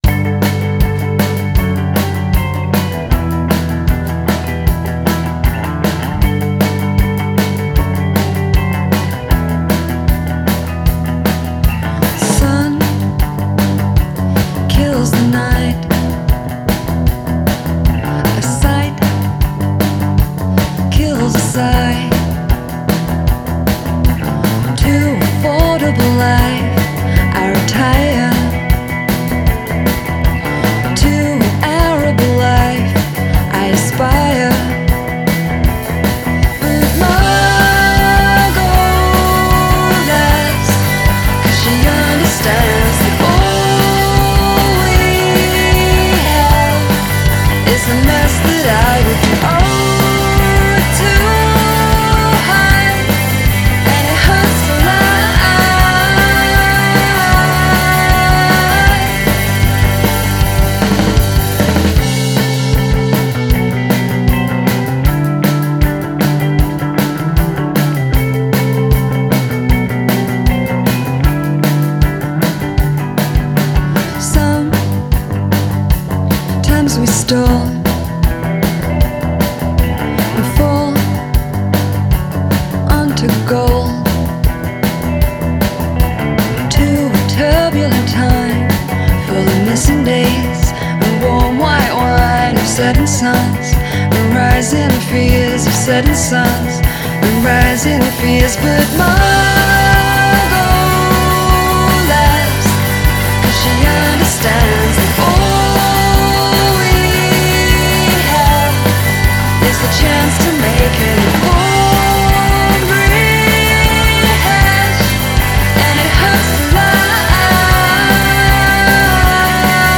The skinny: An EP of rocking songs!
rock pop ditties